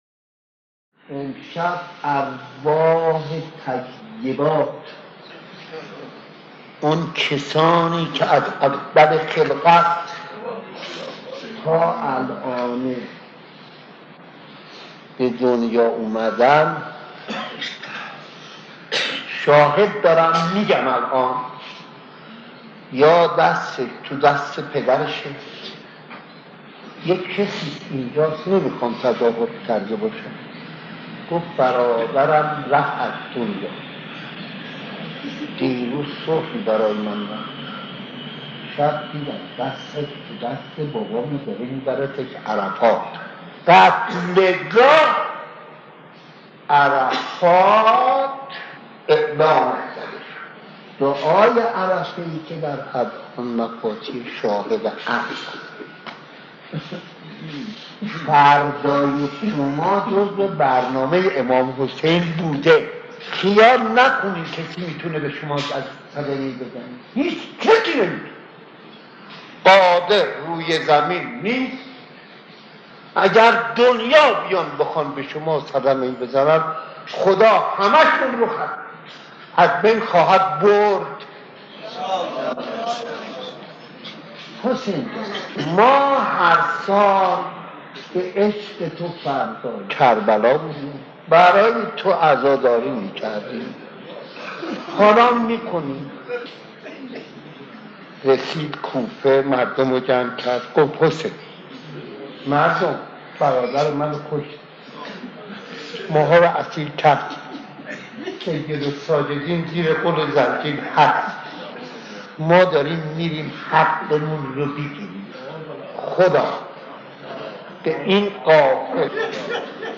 در پرده عشاق، صدای مداحان و مرثیه‌خوانان گذشته تهران قدیم را خواهید شنید که صدا و نفس‌شان شایسته ارتباط دادن مُحب و مَحبوب بوده است.
دعای پس از ذکر زمینه و زمینه‌خوانی سینه‌زنی در شب اربعین